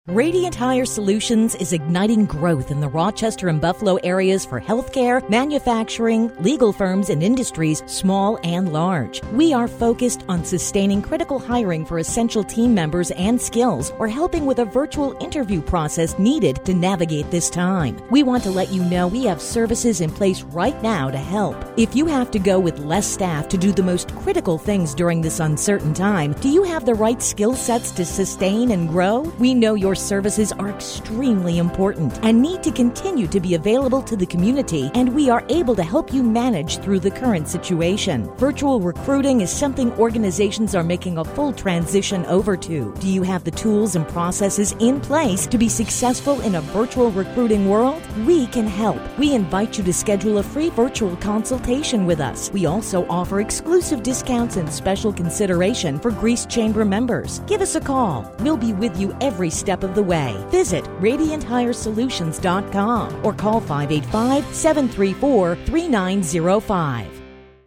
Check out our latest commercial on WYSL . How can we help your business through these uncertain times?